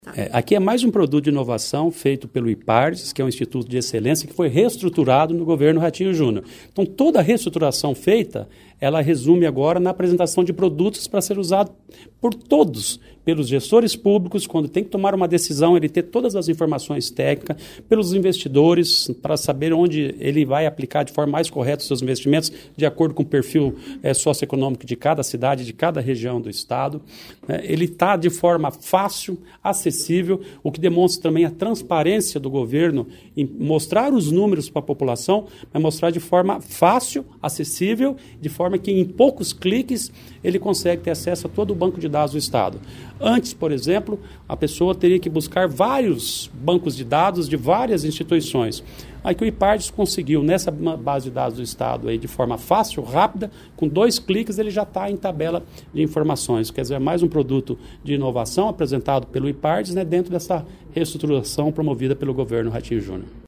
Sonora do secretário Estadual do Planejamento, Ulisses Maia, sobre o Banco de Dados do Estado atualizado